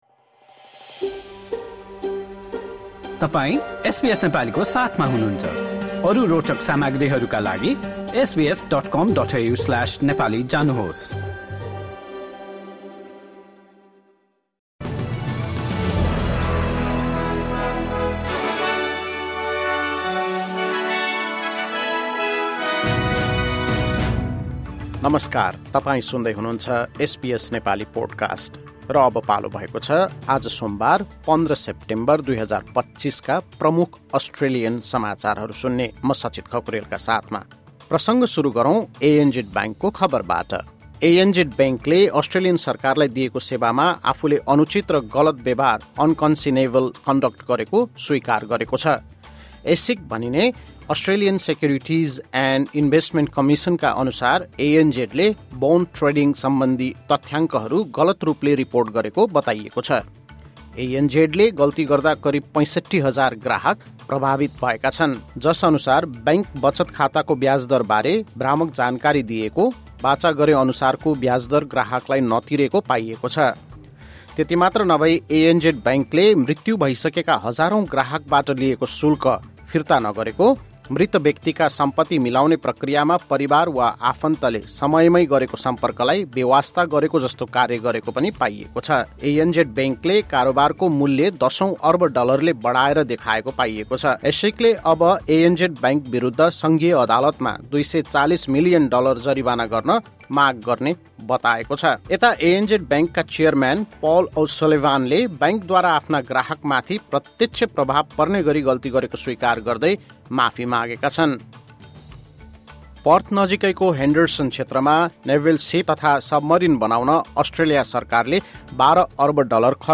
एसबीएस नेपाली प्रमुख अस्ट्रेलियन समाचार: सोमबार, १५ सेप्टेम्बर २०२५